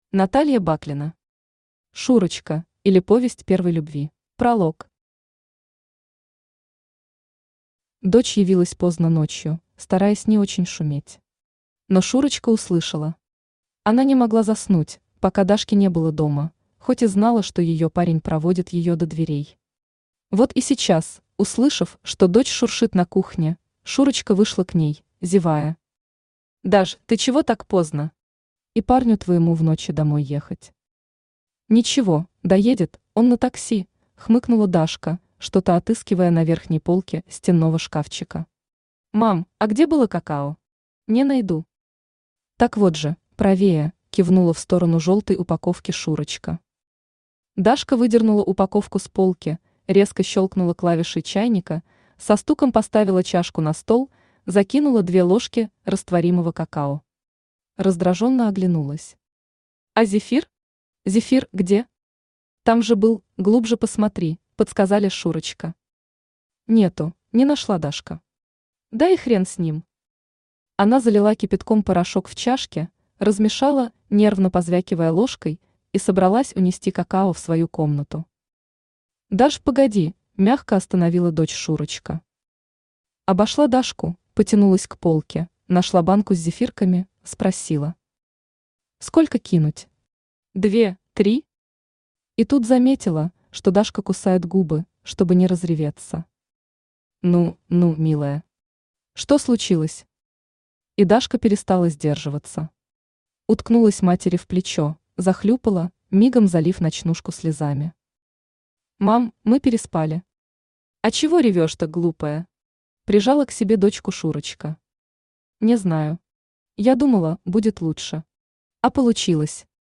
Аудиокнига Шурочка, или Повесть первой любви | Библиотека аудиокниг
Aудиокнига Шурочка, или Повесть первой любви Автор Наталья Баклина Читает аудиокнигу Авточтец ЛитРес.